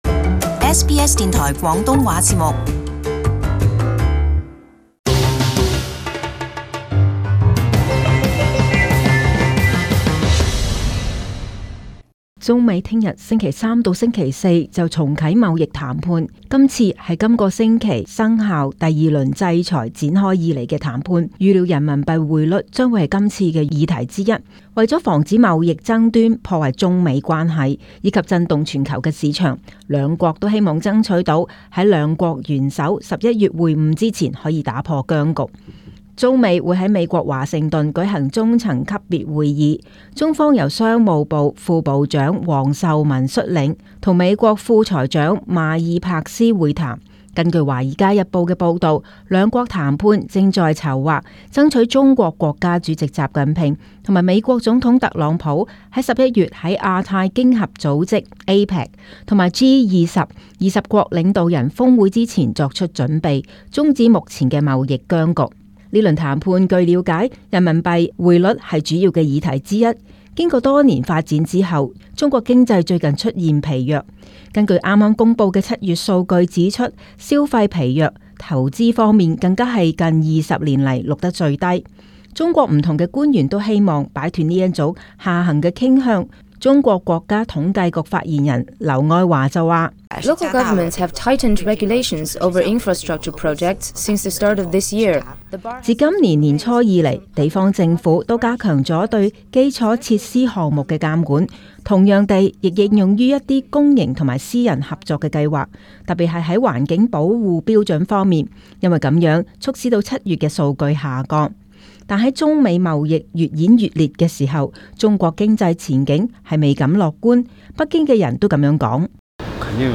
【時事報導】中美貿易談判今周展開為峯會舖路